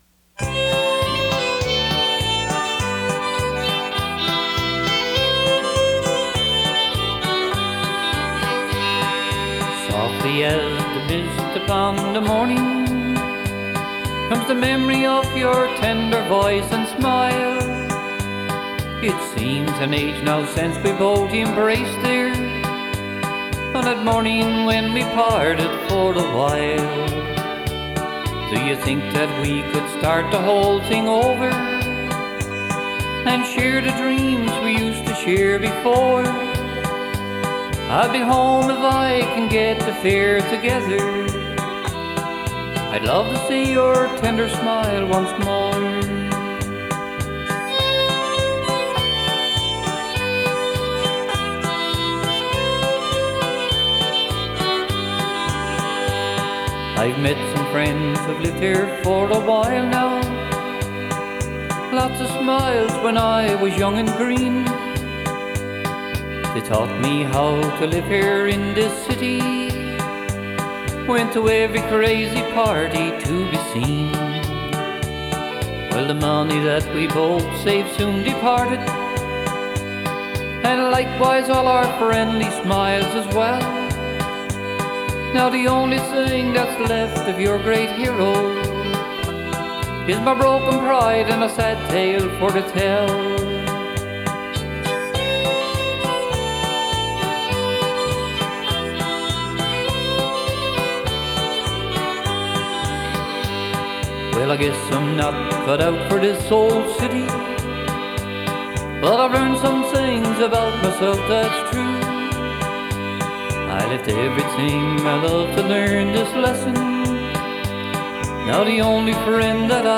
Also, these songs almost all sound the same.